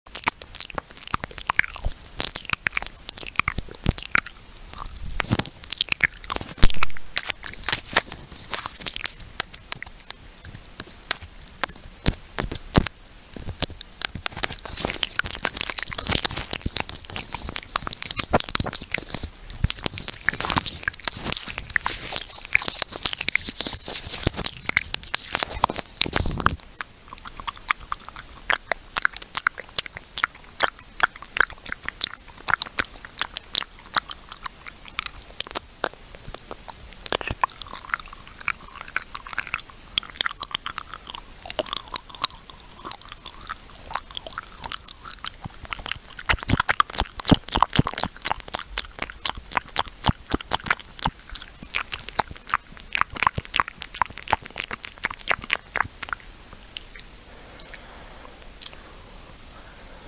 Asmr Mouth sounds for a sound effects free download
Asmr Mouth sounds for a relaxing sleep 😴👄